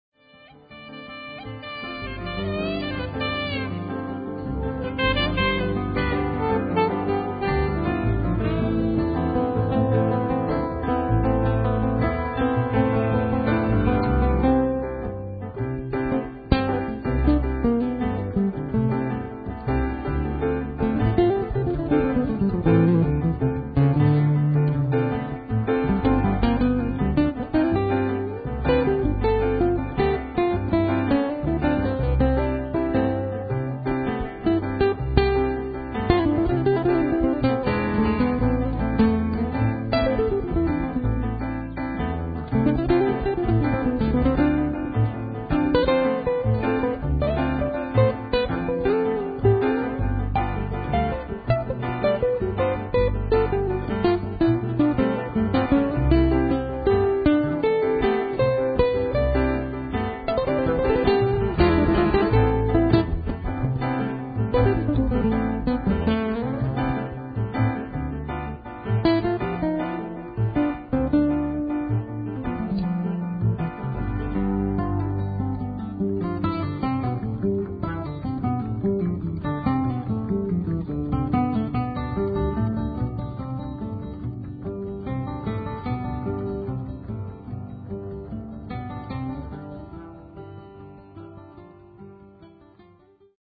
Soprano and alt saxophone
Grand piano
Double bass